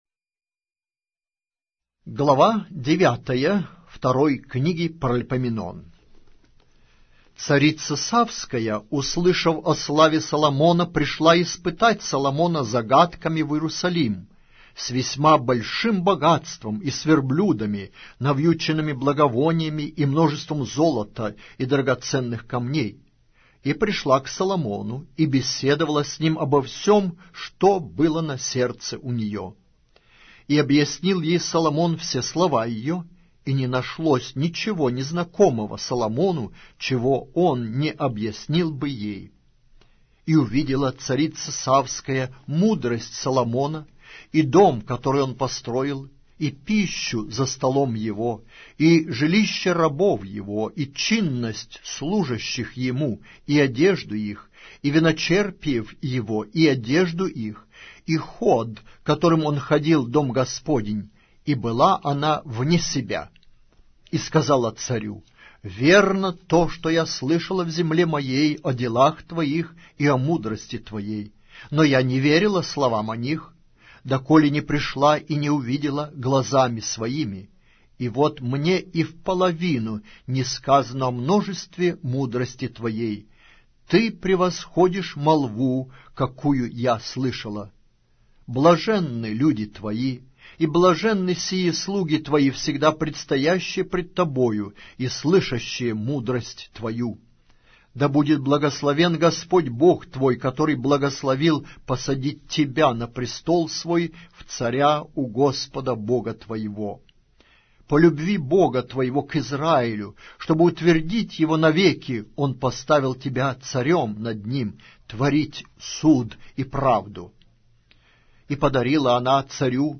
Аудиокнига: 2-я Книга. Паралипоменон